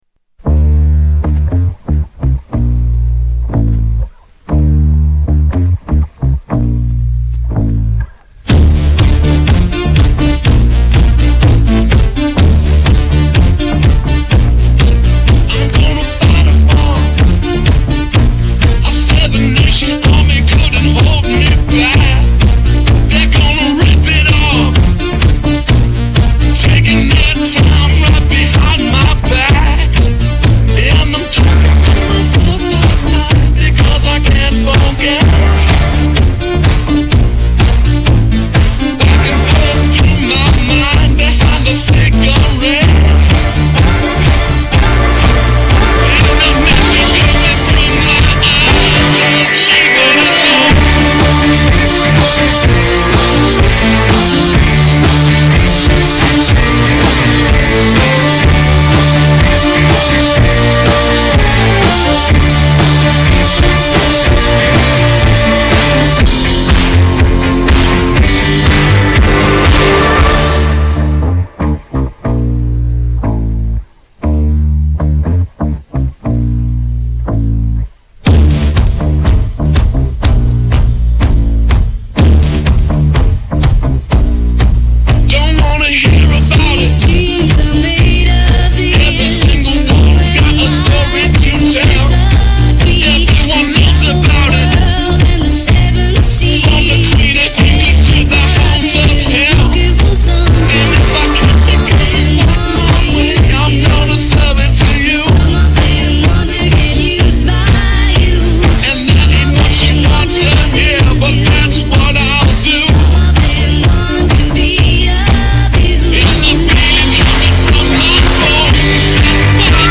Mash up hit songs